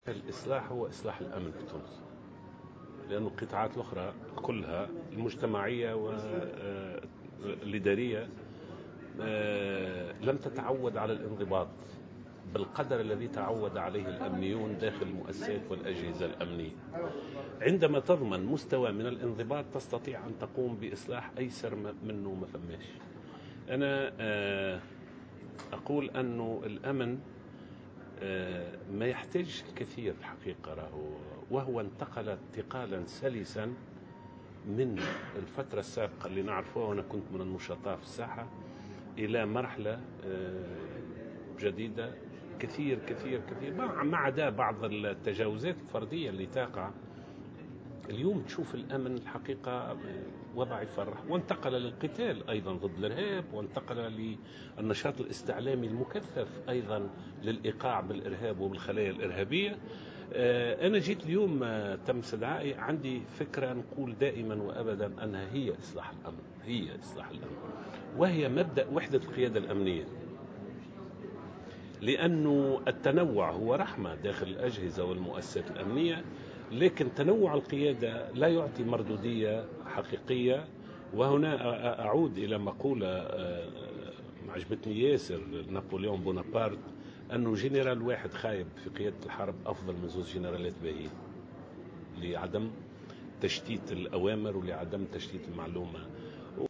و أضاف في تصريح لمراسل "الجوهرة أف أم" على هامش المؤتمر الأول لإصلاح المنظومة الأمنية بحضور خبراء وقضاة وقيادات أمنية، أن جهاز الأمن انتقل انتقالا سلسا خلال الفترة الأخيرة إلى مرحلة جديدة باستثناء بعد التجاوزات الفردية، داعيا في السياق ذاته إلى العمل على توحيد القيادة الأمنية لعدم تشتيت المجهودات والأوامر.